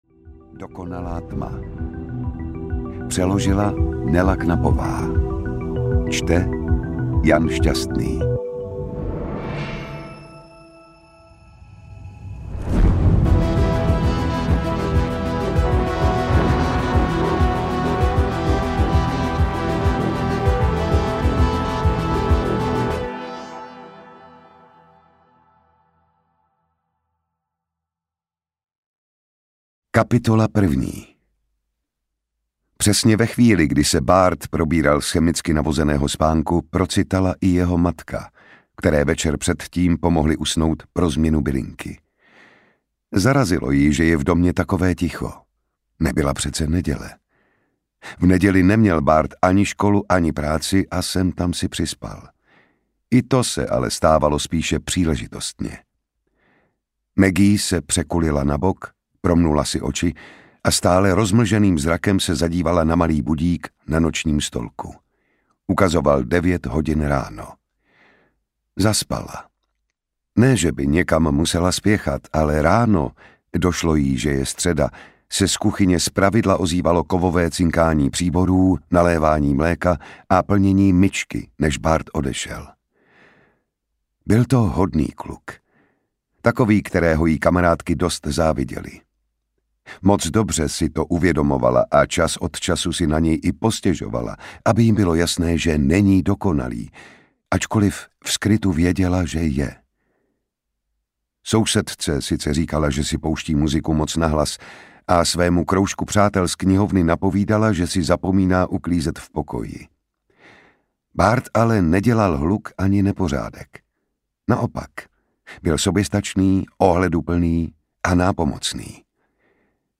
Dokonalá tma audiokniha
Ukázka z knihy
• InterpretJan Šťastný